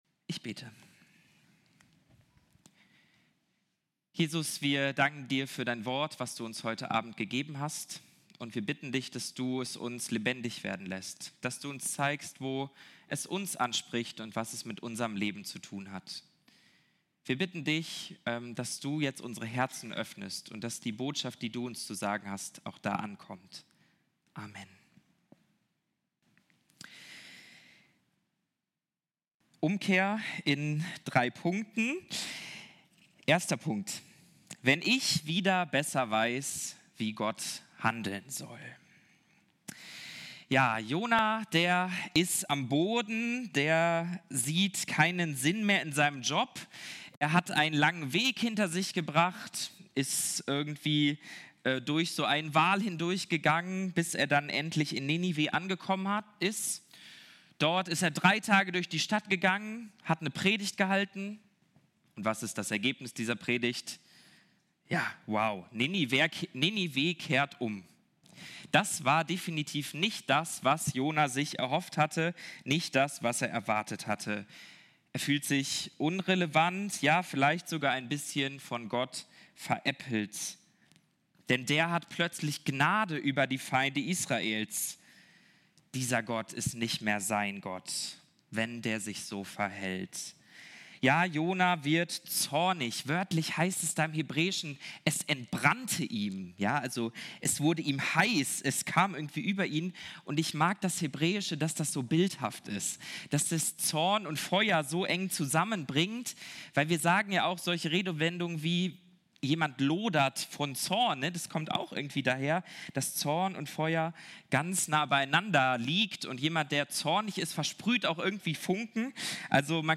Teil 4 der Predigtreihe über Jona.